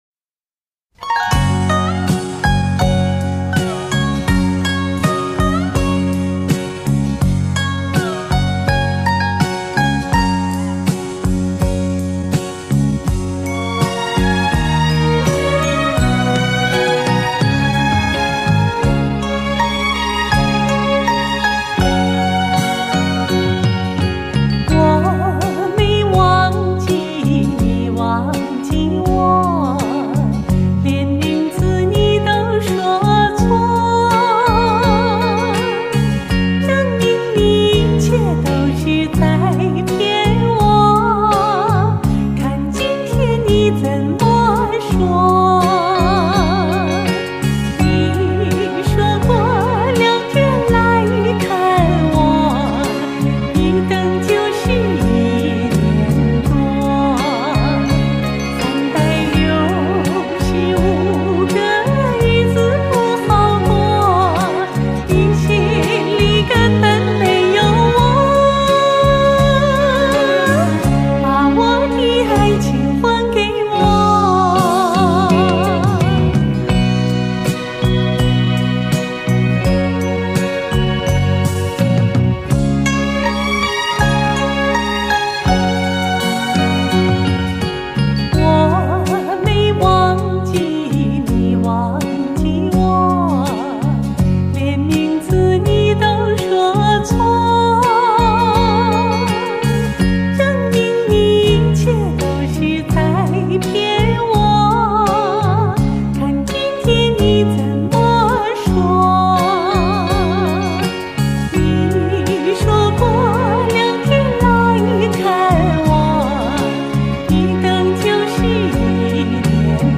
类型: 天籁人声